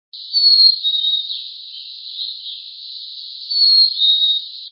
Indian Head Point, Mohican Outdoor Center, Delaware Water Gap, 6/20/01, Chickadee "Fee-bee" song with Tanager in background (54kb)